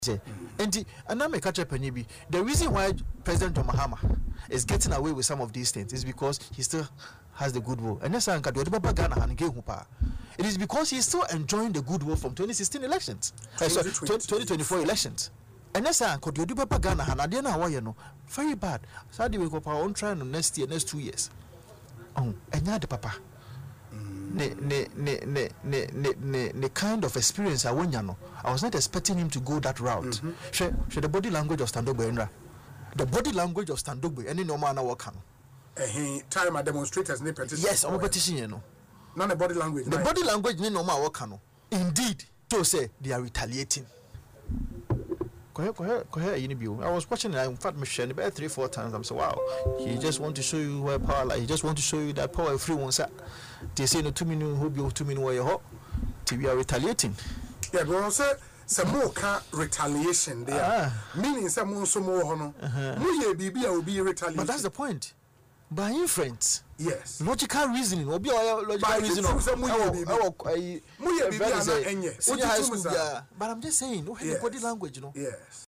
But Mr. Assafuah, speaking on Asempa FM’s Ekosii Sen show , said otherwise.